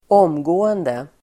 Uttal: [²'åm:gå:ende]